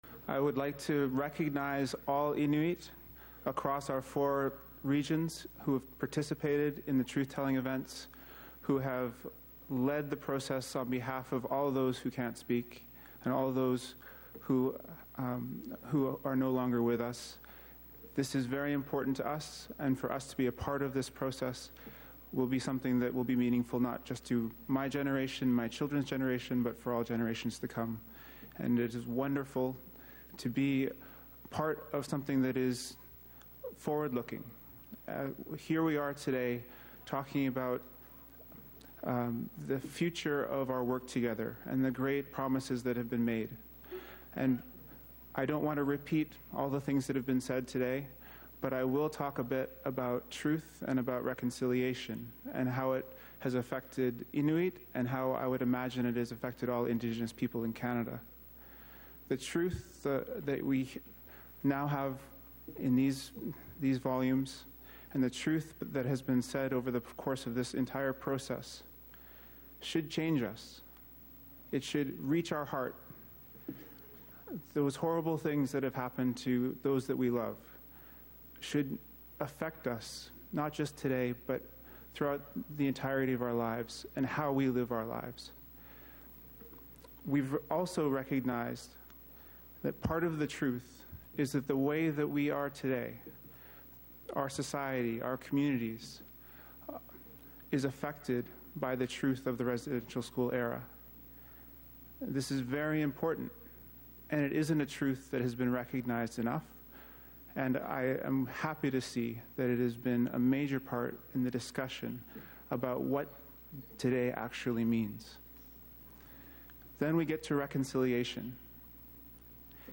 Natan Obed, the president of Canada’s national Inuit organization, Inuit Tapiriit Kanatami,  received cheers and a standing ovation from the crowd at Tuesday’s ceremony after he praised the commission’s work and how it highlighted the role the residential school legacy still played in the personal struggles of many families and communities.
Listen to the full speech from Natan Obed, president of Canada’s national Inuit organization, Inuit Tapiriit Kanatami, at the Truth and Reconciliation Commission’s Final Report ceremony on Tuesday: